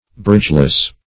Bridgeless \Bridge"less\, a.